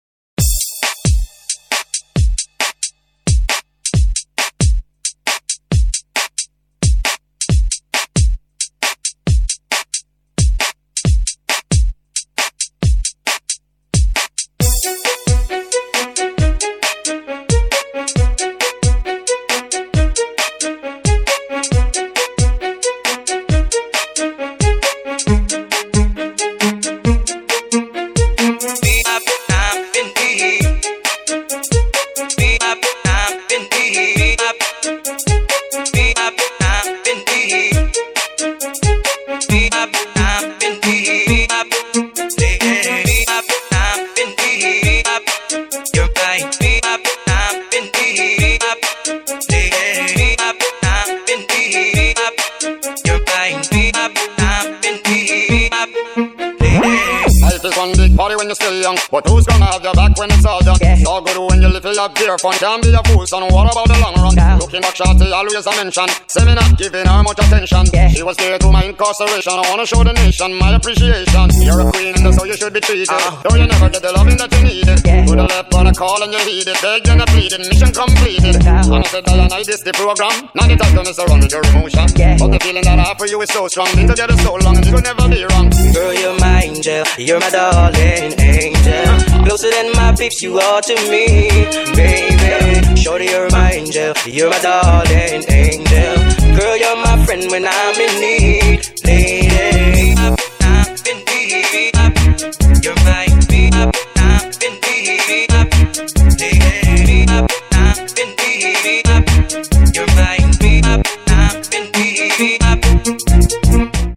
BPM135--1
Audio QualityPerfect (High Quality)